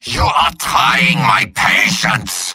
Robot-filtered lines from MvM.
{{AudioTF2}} Category:Medic Robot audio responses You cannot overwrite this file.